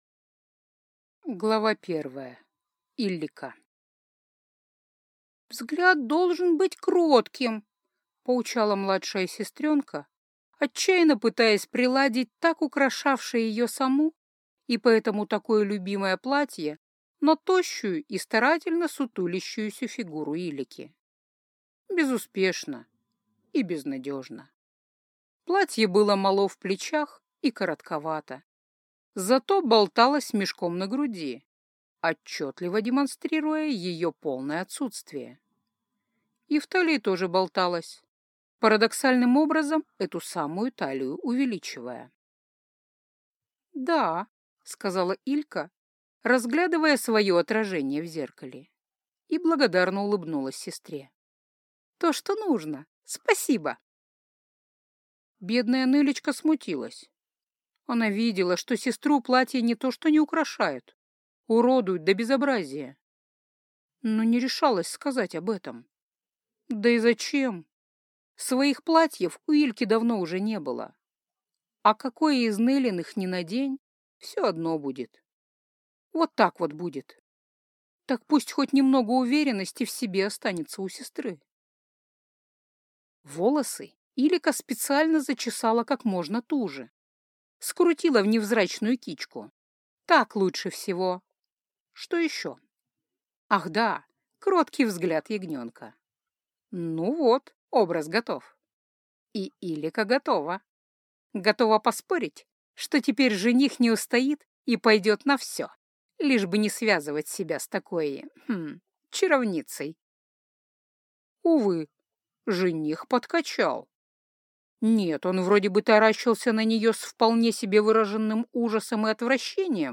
Аудиокнига Иллика и Оккар | Библиотека аудиокниг